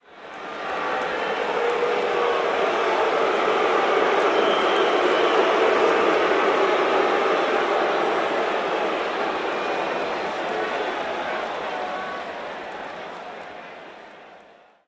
am_crowd_boo_02_hpx
Very large crowd boos in anger during a pro football game in an outdoor stadium.